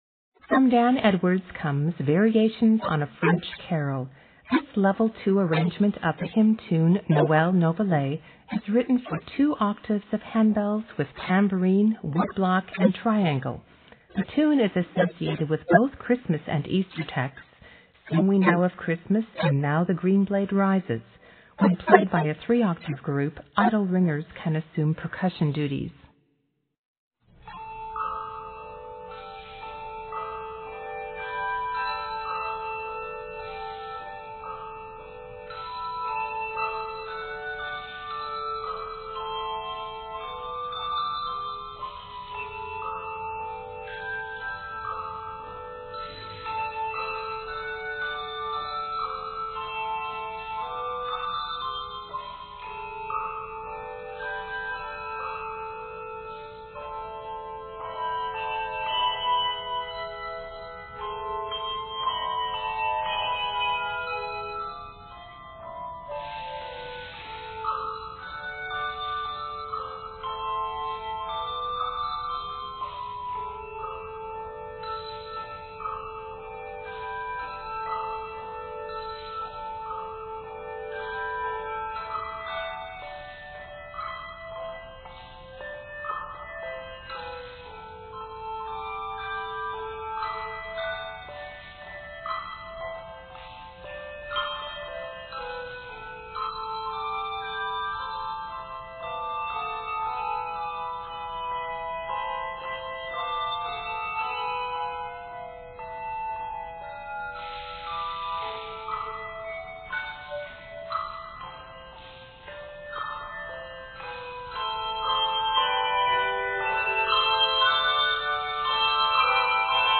Percussion is Tambourine, Wood block & Triangle.